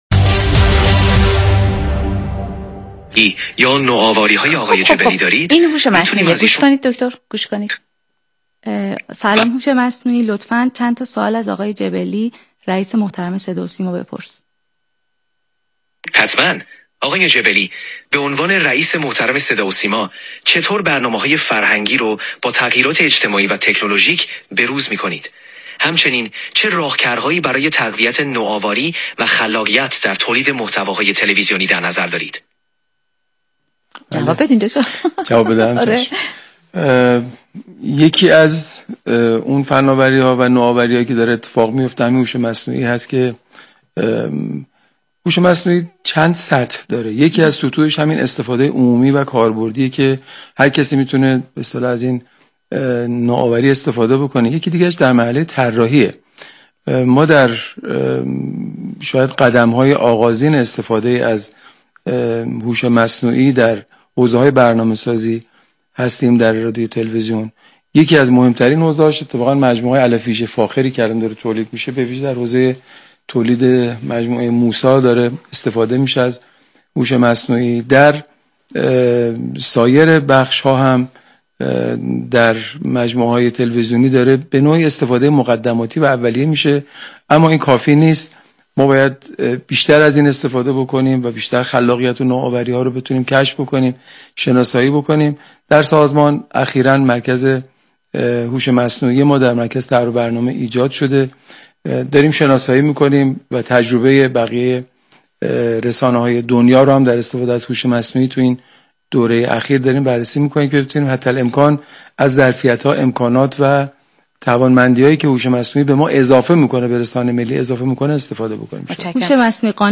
پیمان جبلی در یک برنامه رادیویی به پرسش های مطرح شده توسط هوش مصنوعی پاسخ داد.
به گزارش جام جم آنلاین، رئیس سازمان صداوسیما صبح امروز یکشنبه ۱۸ آذر با حضور در استودیوی پخش «رادیو تهران» و در قالب برنامه صبحگاهی «بام تهران» درباره عملکرد سازمان صداوسیما در حوزه‌های مختلف توضیحاتی را ارائه داد.
پیمان جبلی در بخشی از این گفتگو به پرسش‌هایی که توسط سامانه هوش مصنوعی طراحی و به صورت زنده در استودیو پخش شد پاسخ داد.